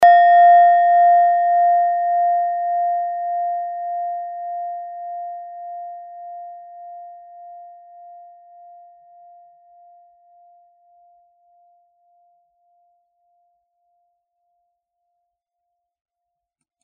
Klangschale Nepal Nr.22
Klangschale-Durchmesser: 11,0cm
(Ermittelt mit dem Filzklöppel)
klangschale-nepal-22.mp3